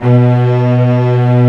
STR STRING04.wav